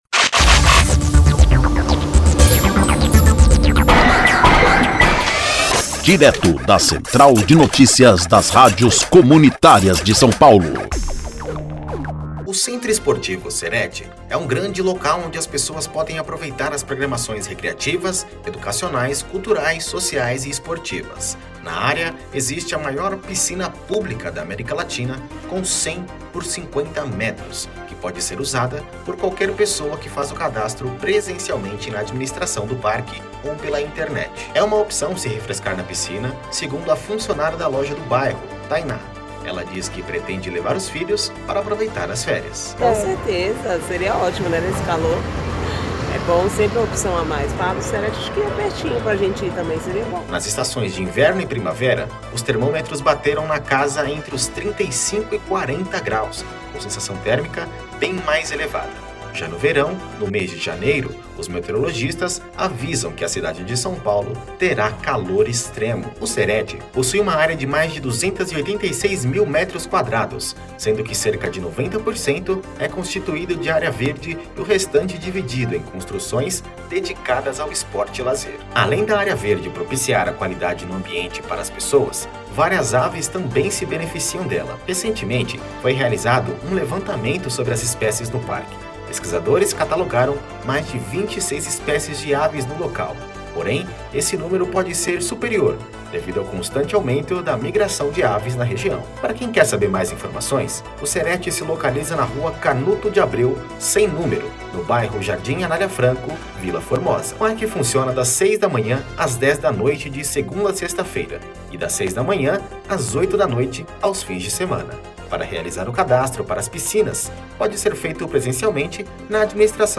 INFORMATIVO: CERET oferece muitas opções de lazer para população